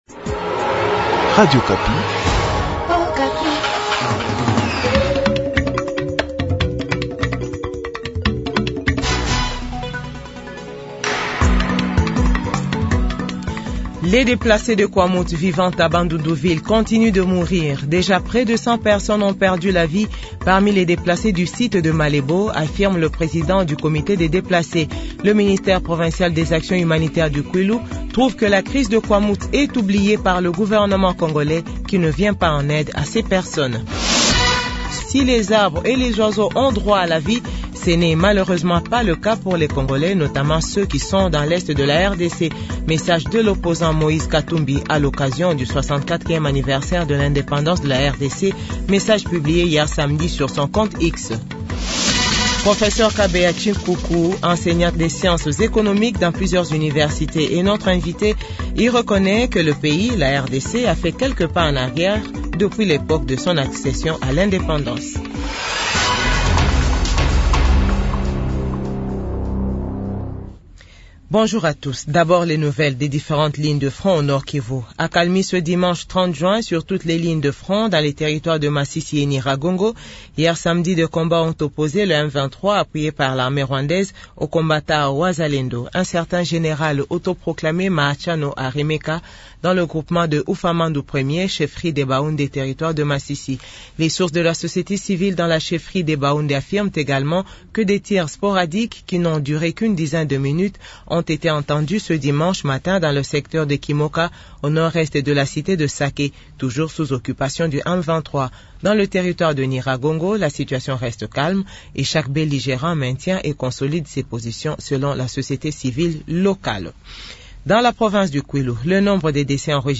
JOURNAL FRANCAIS 15H00